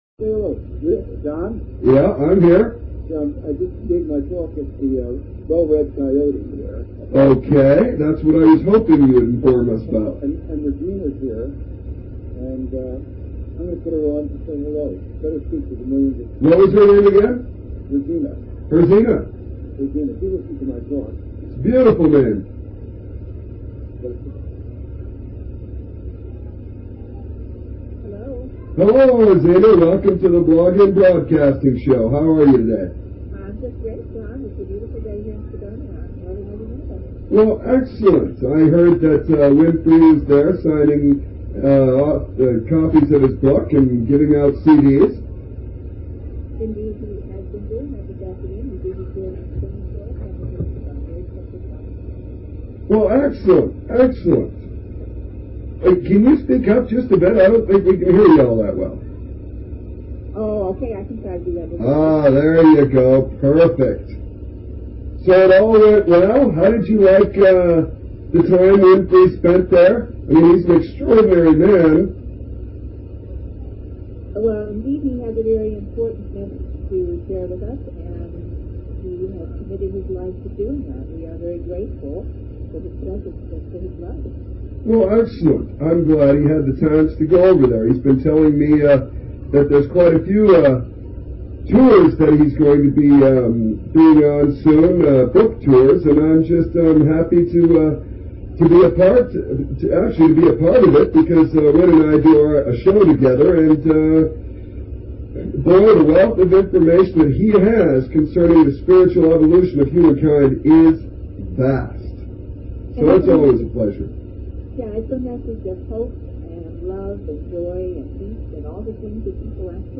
Just Running Amok.... Plus a RA Channeling (Low Quality)